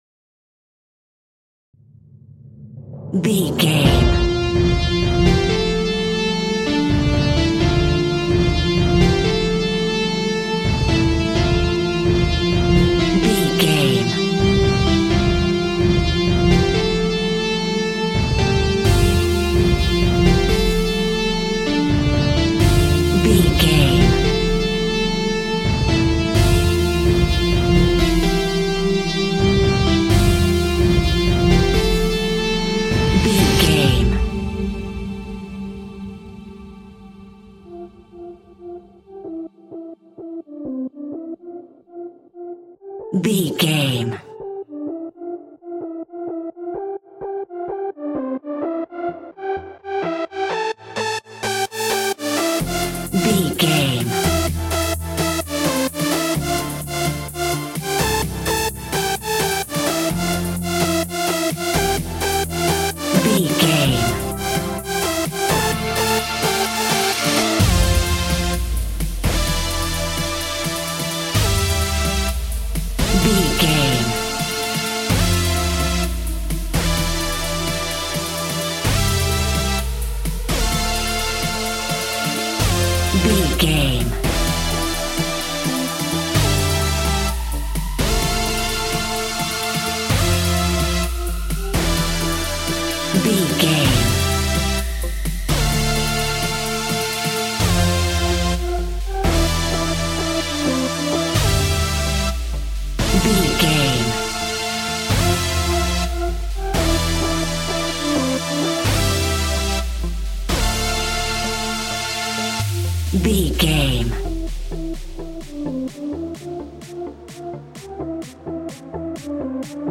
Aeolian/Minor
D
groovy
futuristic
uplifting
drum machine
synthesiser
acid house
electronic
uptempo
synth leads
synth bass